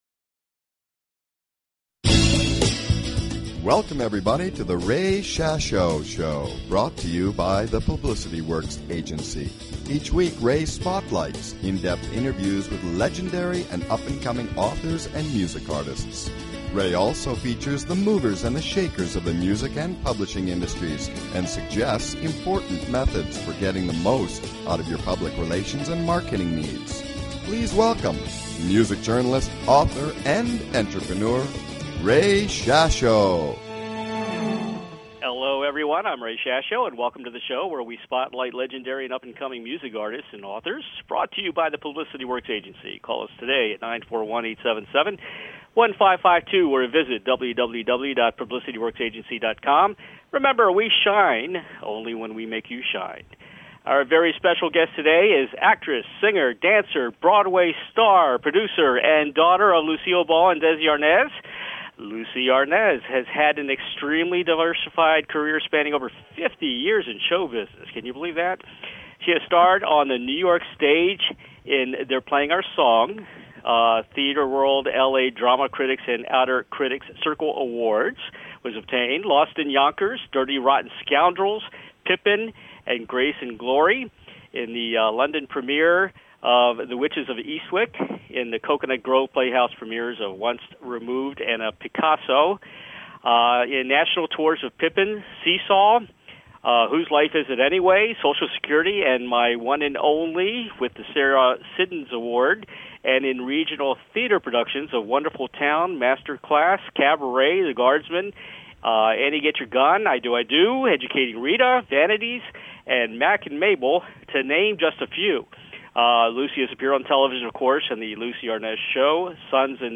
Guest, Lucie Arnaz